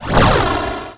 spell_a.wav